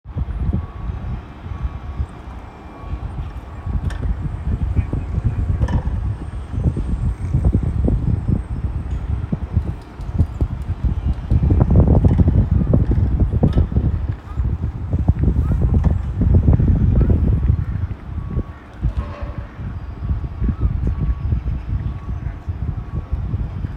I went on several sound walks and found myself in areas that were more tucked away or not as obvious to the daily commuter.
There was more of distant hum of industrial sounds and as I was high up, so the wind was the dominant sound in the 10 minute period , however it was much softer than I expected, and I enjoyed the feeling of the light breeze whilst drawing, so this sketch ended up being much softer, with lighter strokes and smoother more fluid curves.
Four-story Car Park Sketch